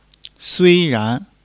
sui1-ran2.mp3